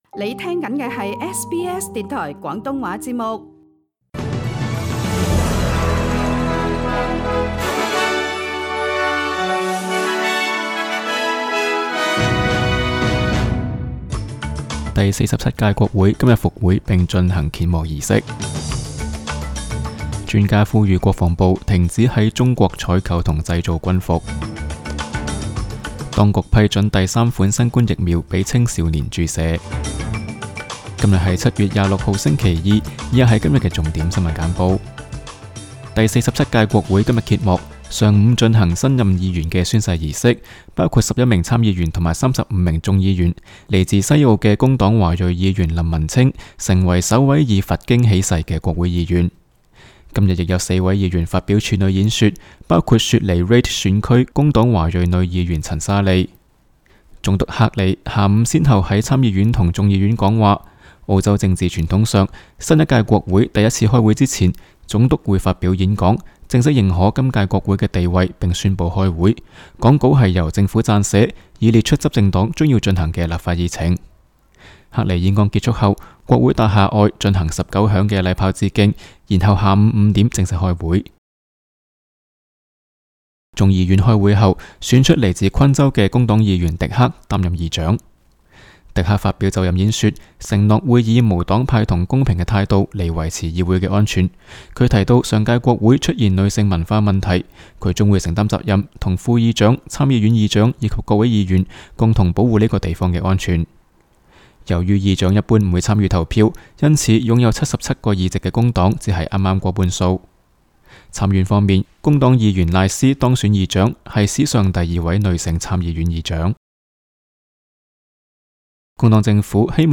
SBS 廣東話節目新聞簡報 Source: SBS Cantonese